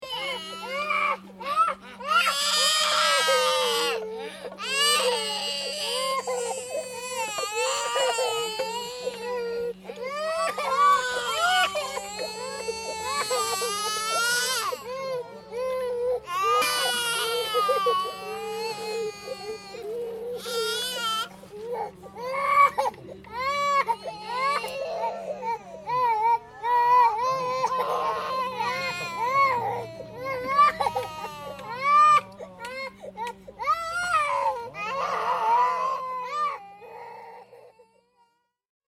東光寺の裏山、湯の峯王子に到着して父親の肩から下ろされると、稚児たちは再び超不機嫌。